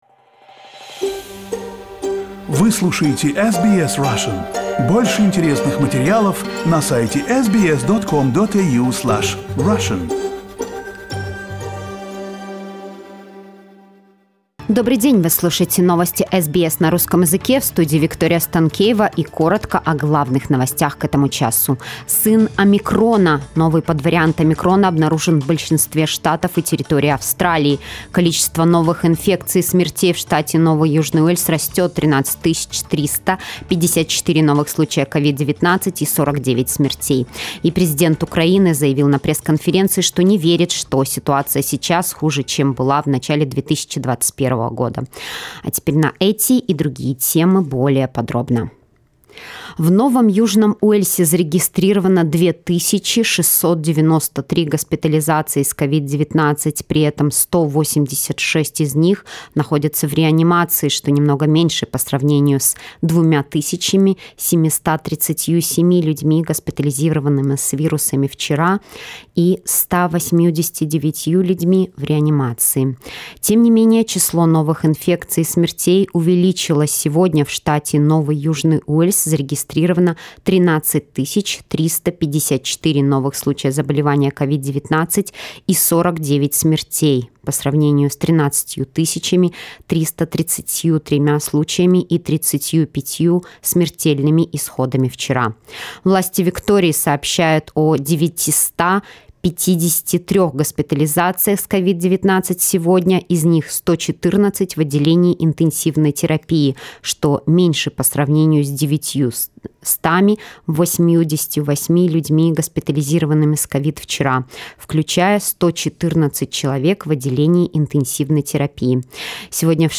SBS news in Russian - 29.01